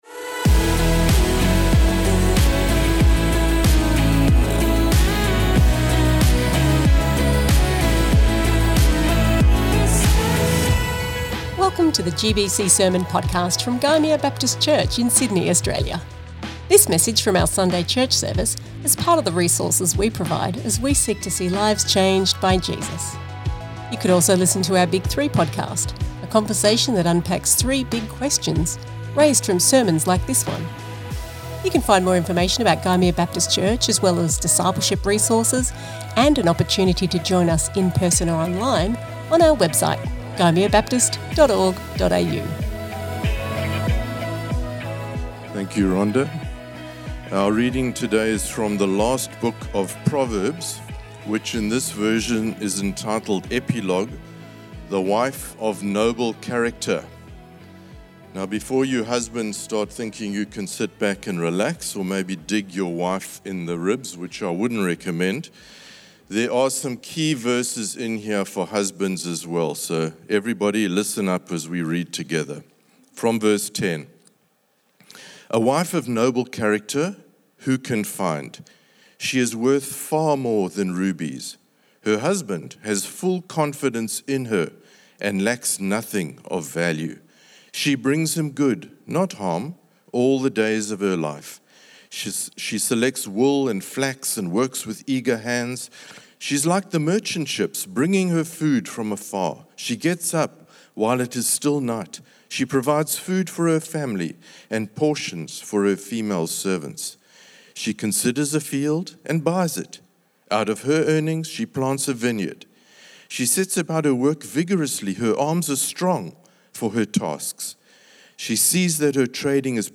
This message from our Sunday church service is part of the resources we provide as we seek to see lives changed by Jesus.